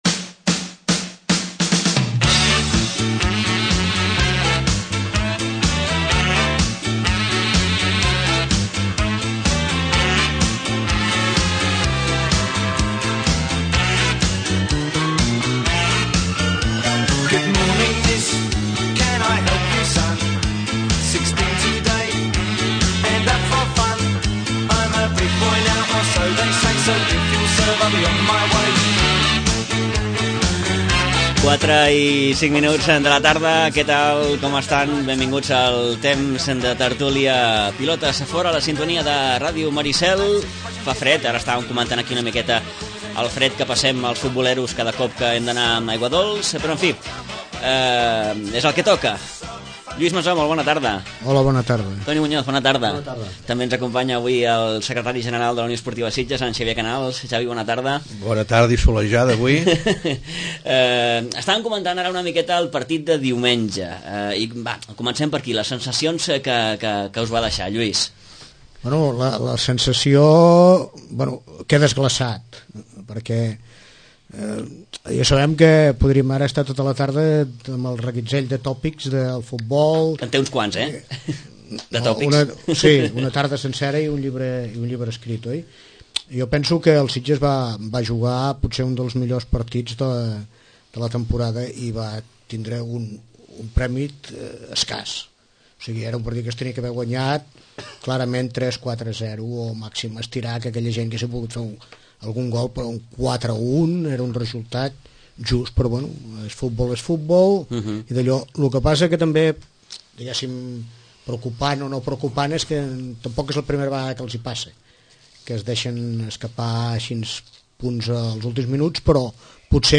Emissora municipal de Sitges. 107.8FM. Escolta Sitges.